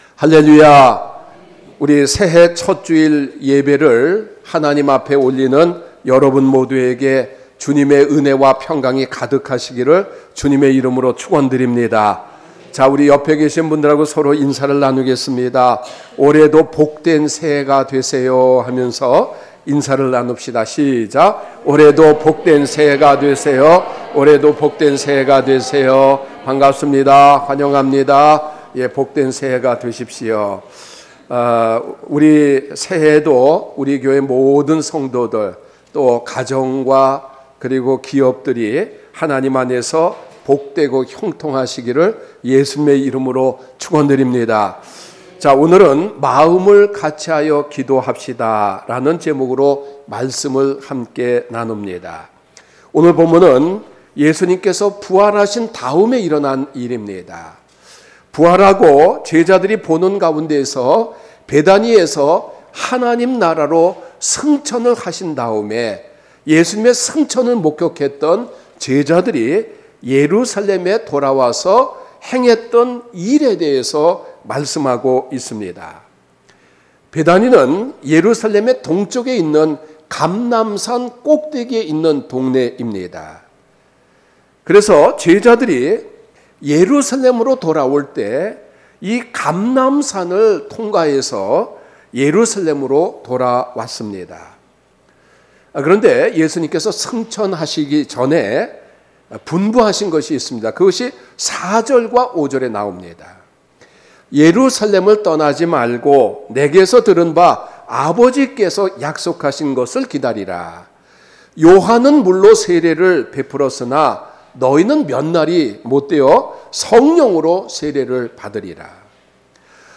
목록 share 주일설교 의 다른 글